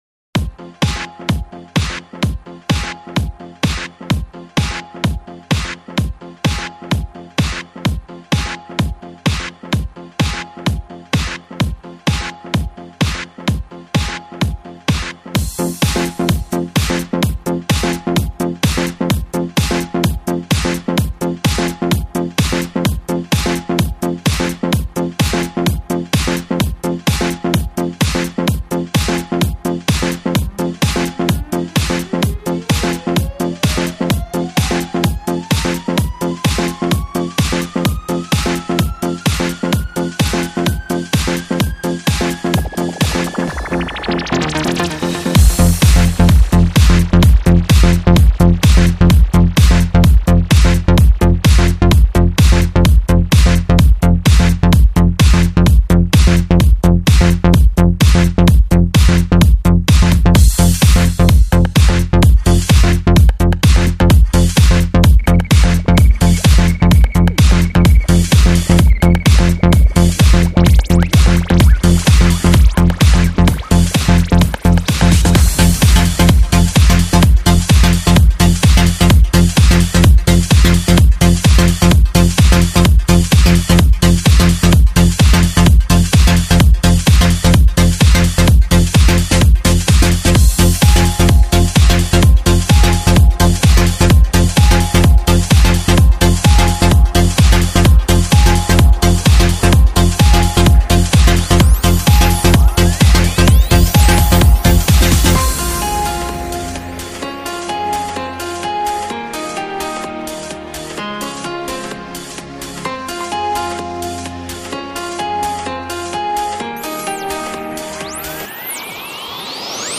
*** просто потрясающий trance 2008года ***